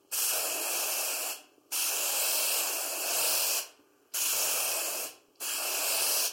Шипящий звук распыления краски из баллончика